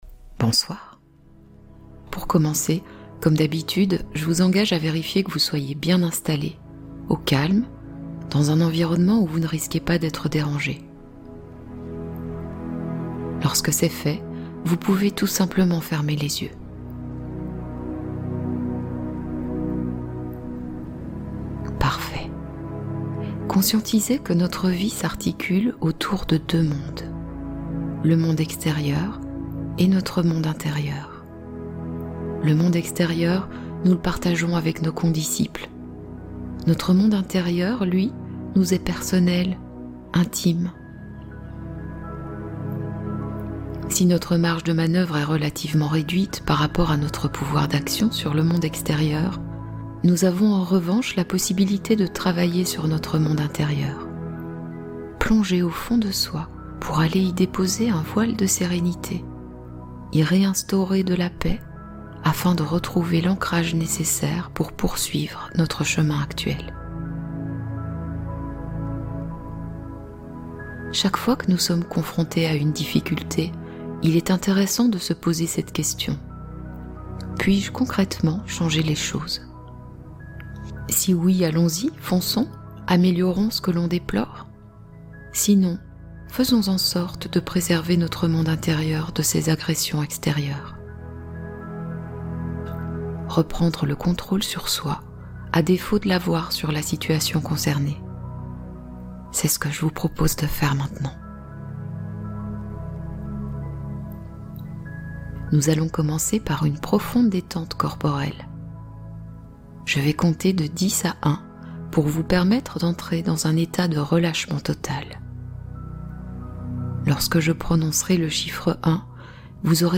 Attirez le positif toute la nuit | Hypnose sommeil profond et réparateur + manifestation nocturne